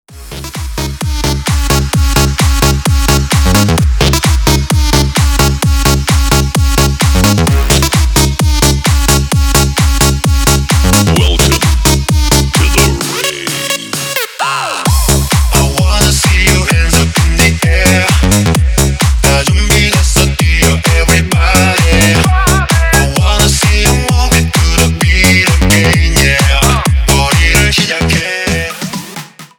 • Качество: 320, Stereo
Club House
энергичные
клубные